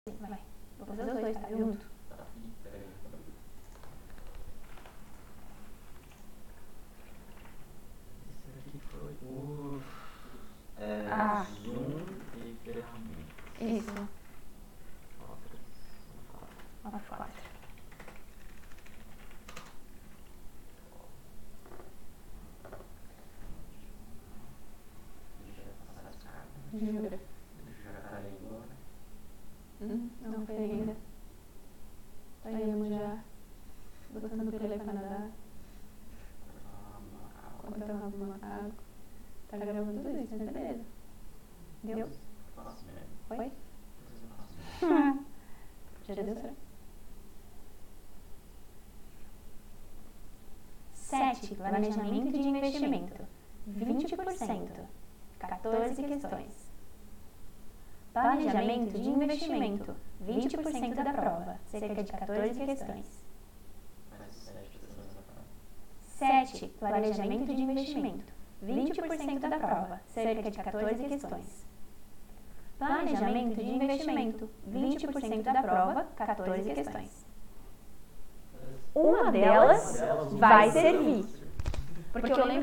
Duplicated voice on a audio recoring. There's a way to fix it?
So, i was doing a voice over record last night and for some reason the file had a duplicated voice (just like a echo, but "messier"). My first tought was that one of the channels was dalayed and i just need to replace, but there's only one channel with audio info, the other one is completely noise.
Yes, unfortunately the echo is so fast and loud there is no way to remove it that I currently know of.
There is some hiss on your recording track too so perhaps using the Noise Reduction or DeNoise tools will help when you re-record too.